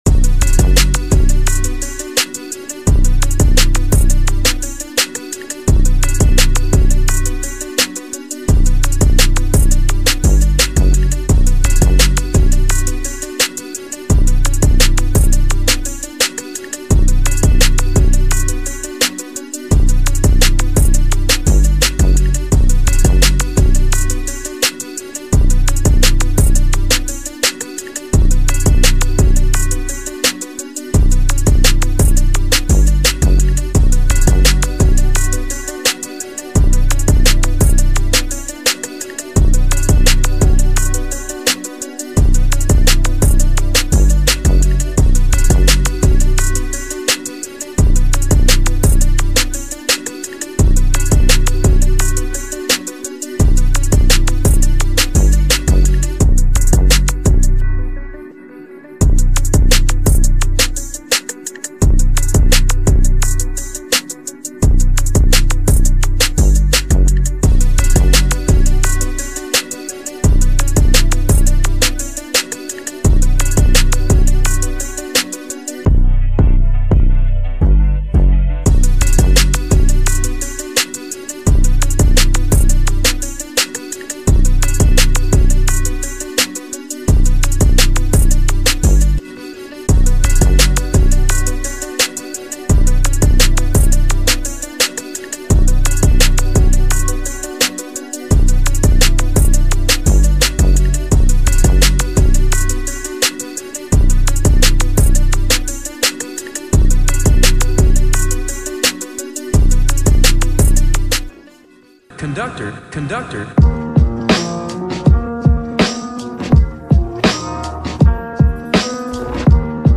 بی‌کلام
Hip-Hop Disstrack